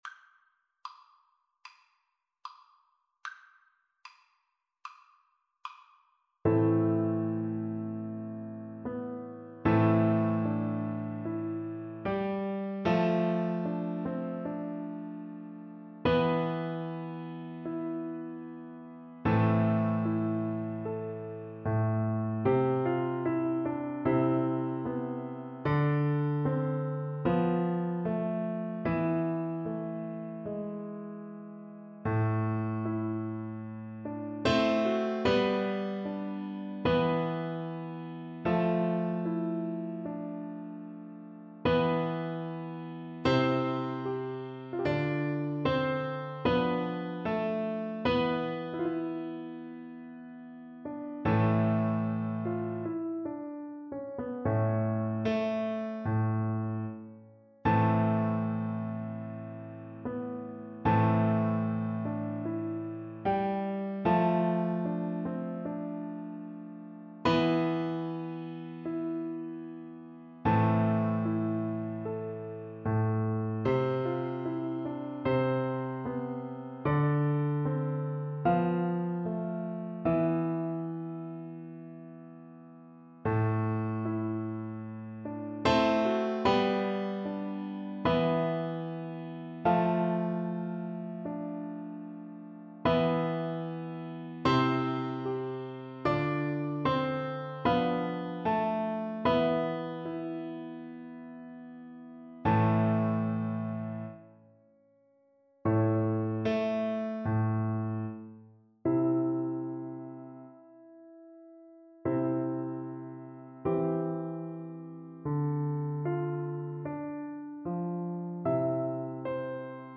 Play (or use space bar on your keyboard) Pause Music Playalong - Piano Accompaniment transpose reset tempo print settings full screen
Violin
Arrangement for Violin and Piano
A minor (Sounding Pitch) (View more A minor Music for Violin )
4/4 (View more 4/4 Music)
Classical (View more Classical Violin Music)